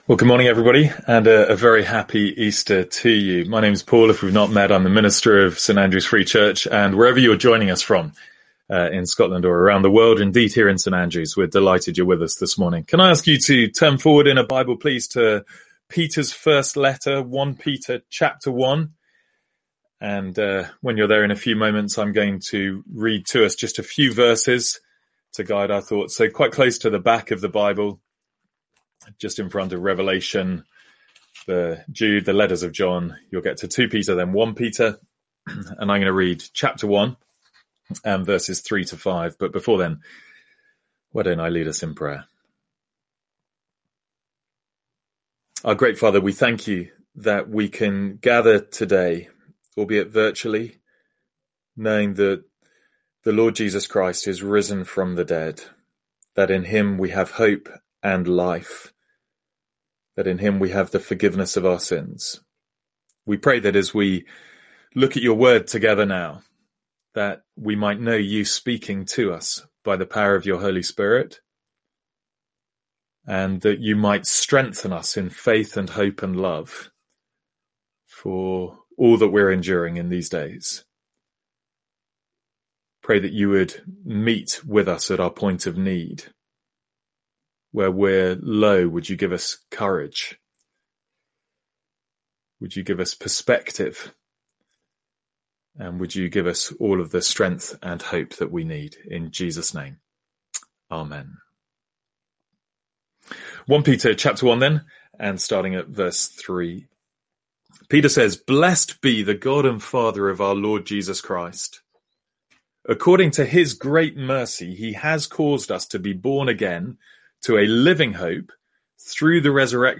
Sermons | St Andrews Free Church
From our Easter Sunday morning service in 1 Peter 1:3-5.